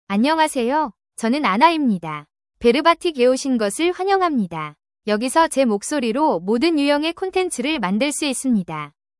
Anna — Female Korean (Korea) AI Voice | TTS, Voice Cloning & Video | Verbatik AI
FemaleKorean (Korea)
Anna is a female AI voice for Korean (Korea).
Voice sample
Listen to Anna's female Korean voice.
Female